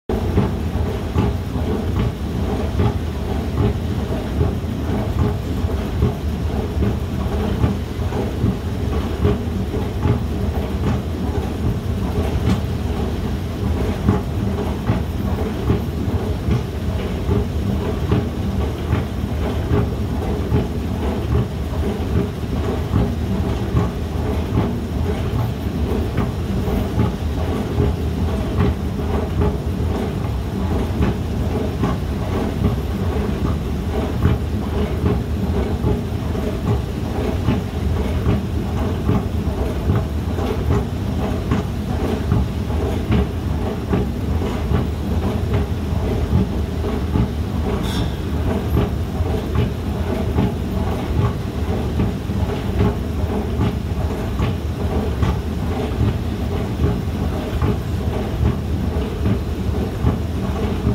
Звуки эскалатора в метро и в торговом центре, подъём и спуск, шум движущихся ступенек mp3 для монтажа видео.
3. Монотонный звук работающего эскалатора для фона в видео